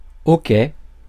Ääntäminen
Vaihtoehtoiset kirjoitusmuodot oki Synonyymit (arkikielessä) d'acc Ääntäminen France (Paris): IPA: [o.kɛ] Haettu sana löytyi näillä lähdekielillä: ranska Käännös Substantiivit 1.